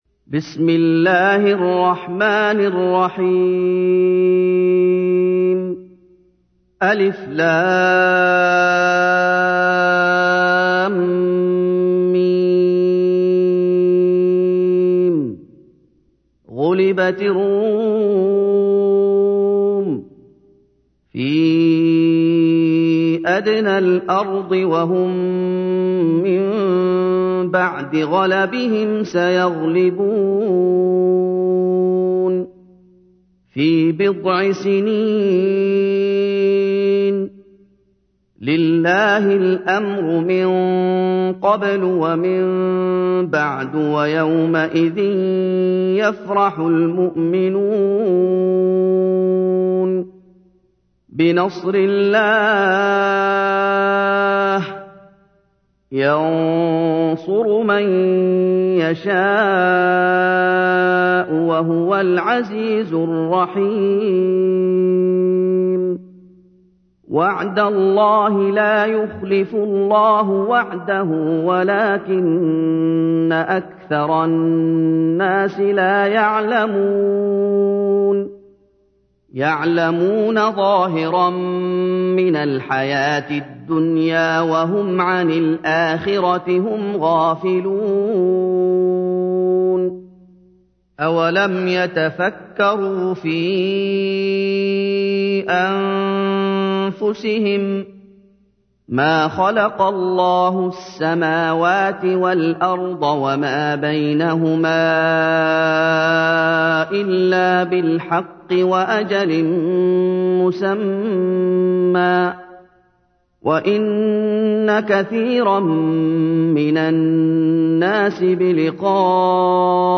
دانلود ترتیل کل قرآن کریم با صدای دلنشین استاد محمد ایوب به تفکیک سوره ها که هر سوره در قالب یک فایل MP3 کم حجم ارائه شده است.